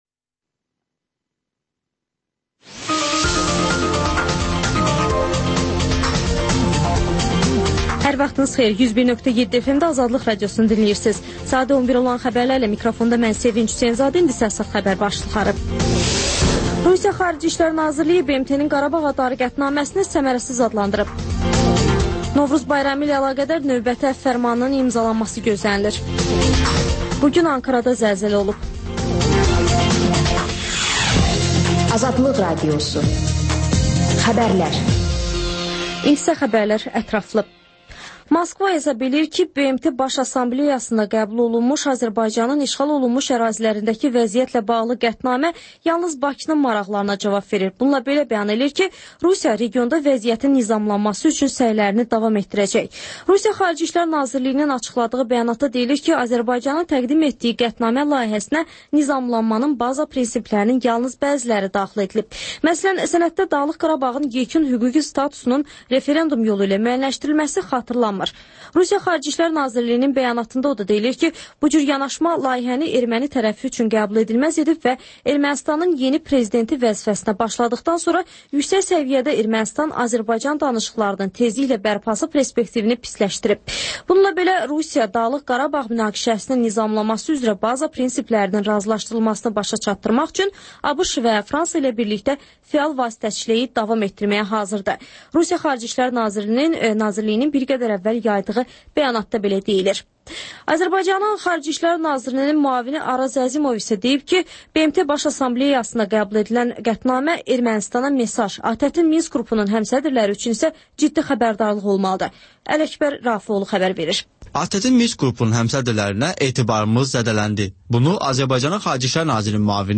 Xəbərlər, ardınca PANORAMA verilişi: Həftənin aktual mövzusunun müzakirəsi.